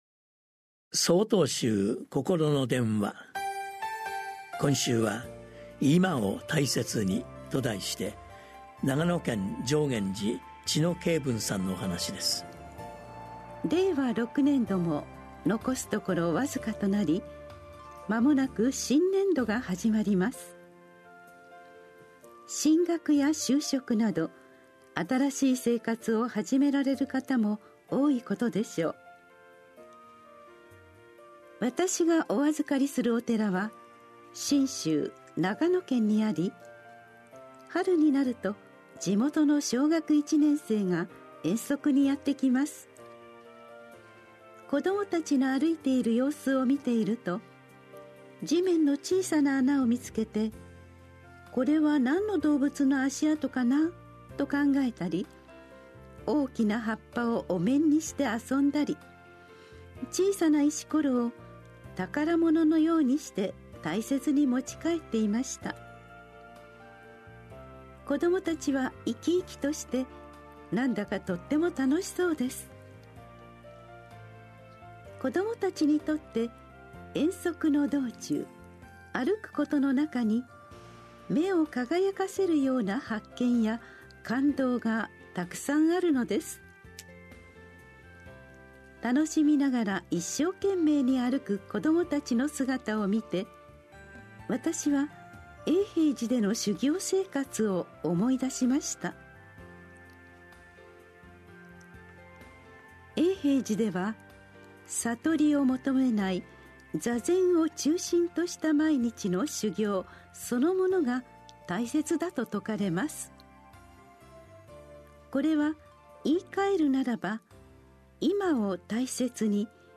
心の電話（テレホン法話）３/25公開『今を大切に』 | 曹洞宗 曹洞禅ネット SOTOZEN-NET 公式ページ